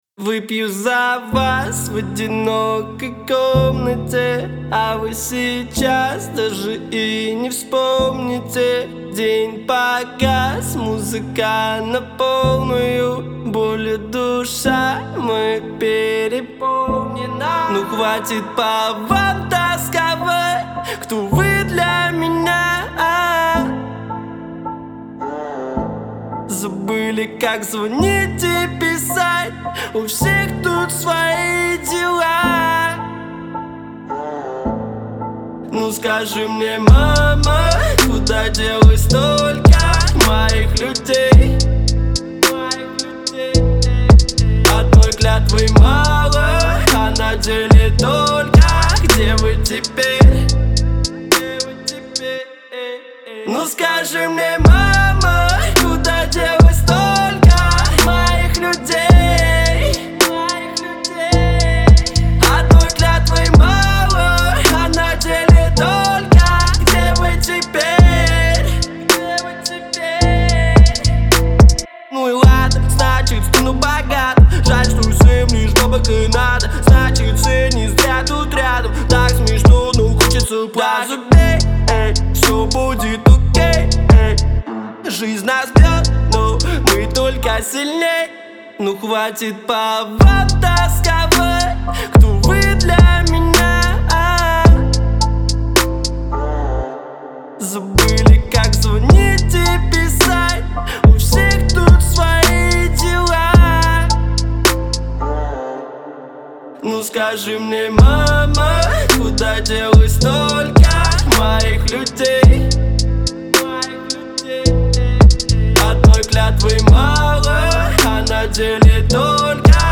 это яркий пример современного русского хип-хопа